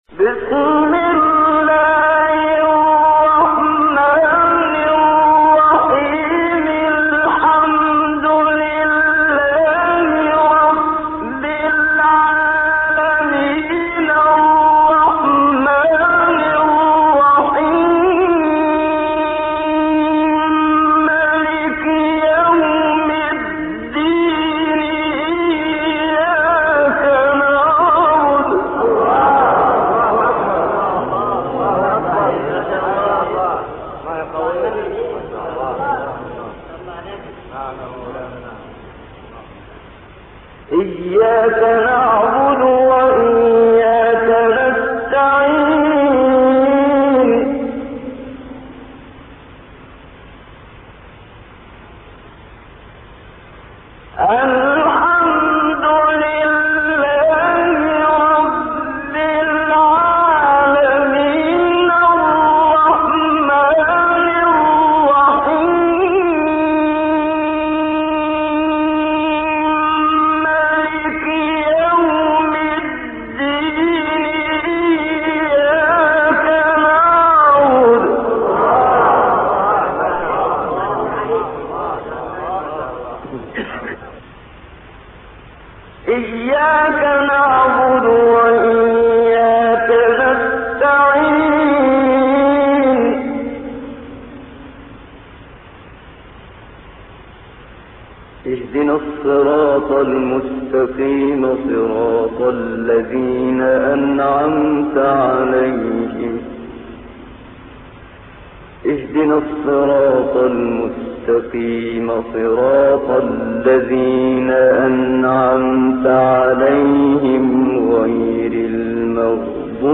تلاوت سوره حمد و آیات ابتدایی سوره بقره منشاوی | نغمات قرآن | دانلود تلاوت قرآن